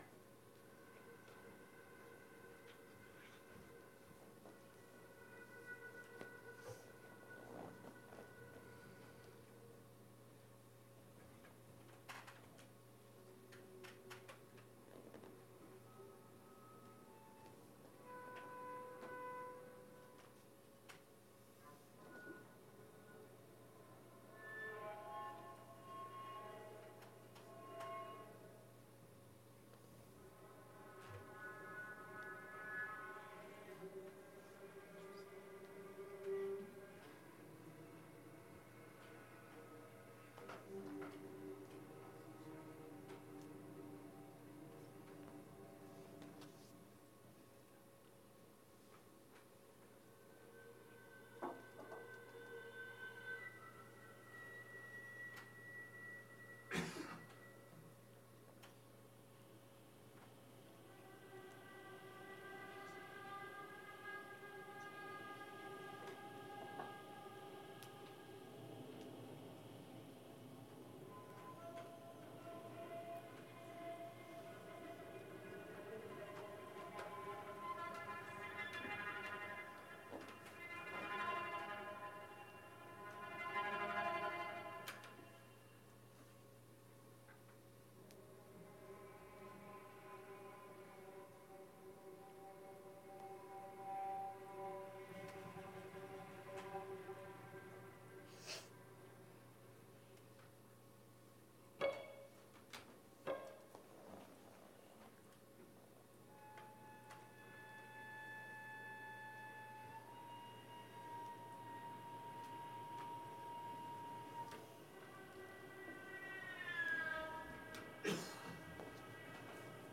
for cello and live electronics (Max/MSP) (14:30)